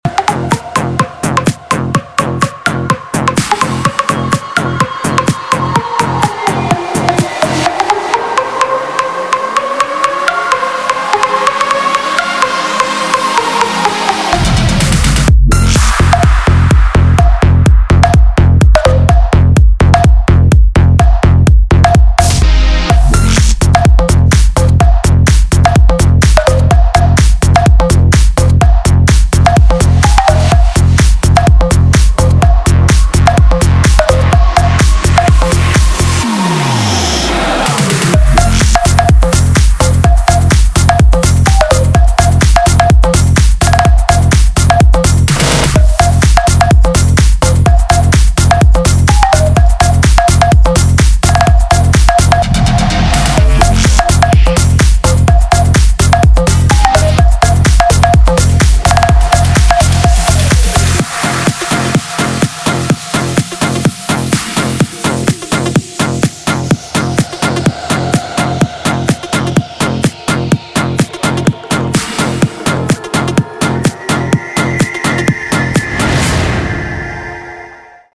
• Качество: 128, Stereo
Отличный Клубняк!